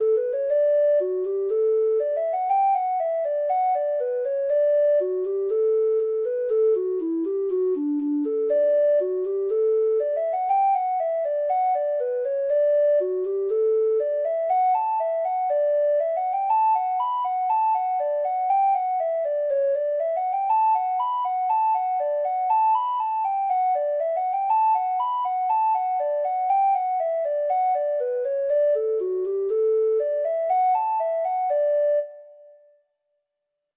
Free Sheet music for Treble Clef Instrument
Reels